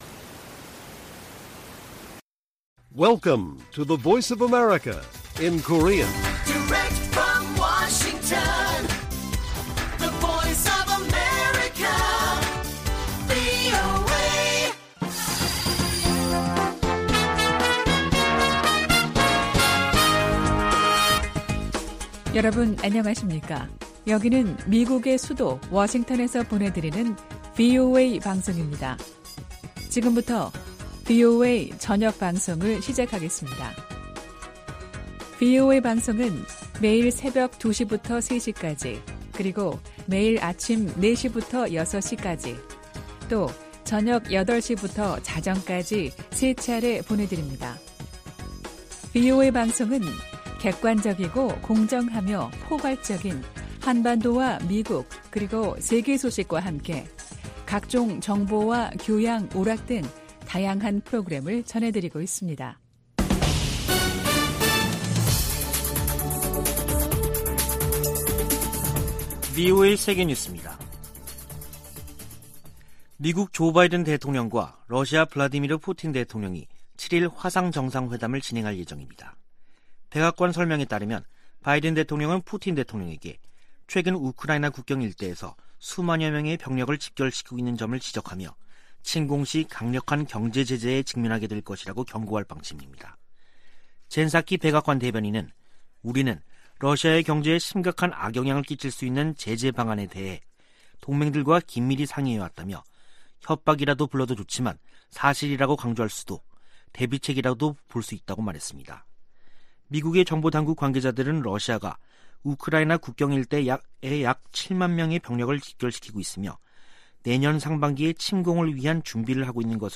VOA 한국어 간판 뉴스 프로그램 '뉴스 투데이', 2021년 12월 7일 1부 방송입니다. 미국이 중국의 인권 탄압을 이유로 내년 2월 베이징 동계올림픽에 정부 공식대표단을 파견하지 않는다고 공식 발표했습니다. 유럽연합(EU)이 북한인 2명과 기관 1곳 등에 인권제재를 1년 연장했습니다. 조 바이든 미국 대통령이 오는 9일과 10일 한국과 일본 등 전 세계 110개국이 참가하는 민주주의 정상회의를 화상으로 개최합니다.